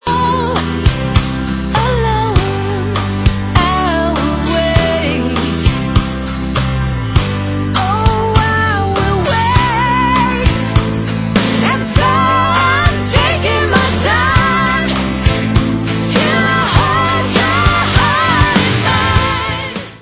sledovat novinky v oddělení Rock